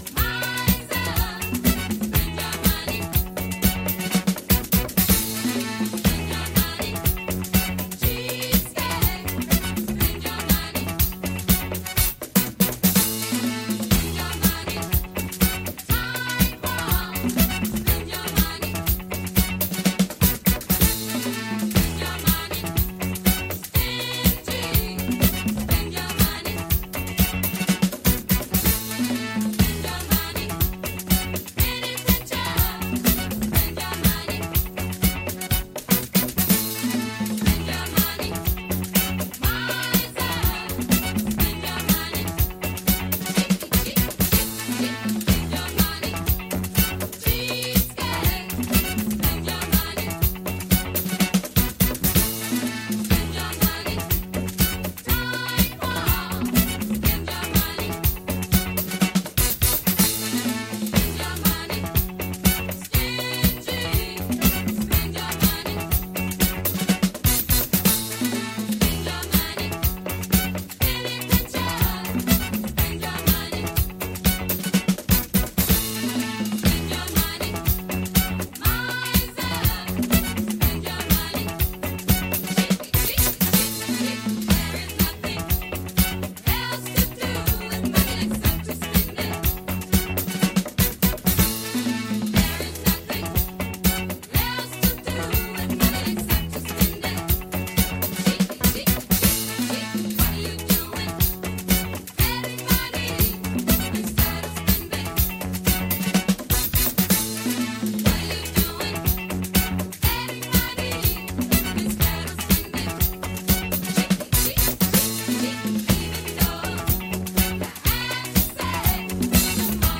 女性ヴォーカルをフィーチャーしたDJ的にもプレイしやすいグルーヴィーなアフロ・ディスコ〜ファンクを展開！